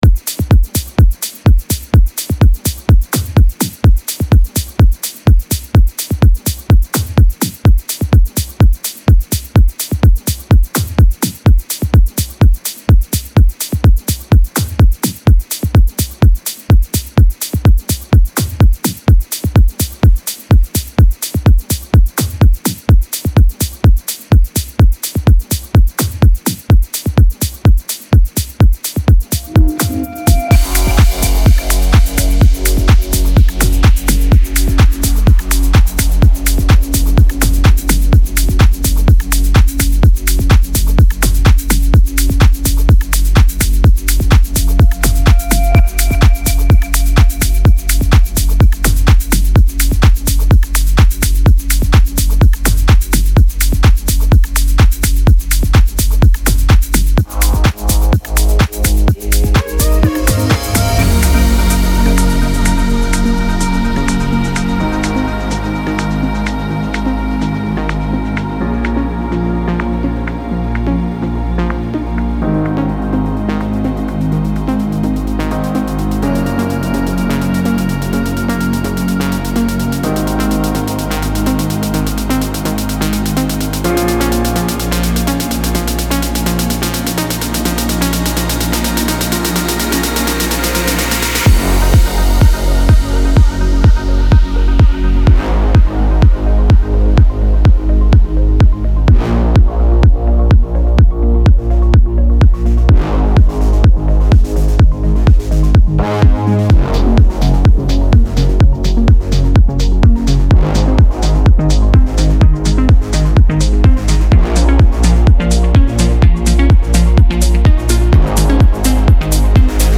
Жанр: House Progressive